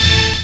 hitTTE68010stabhit-A.wav